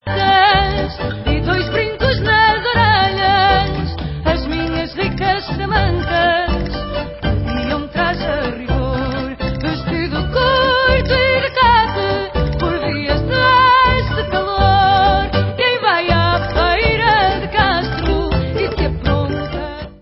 World/Fado